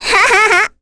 Requina-vox-Happy2.wav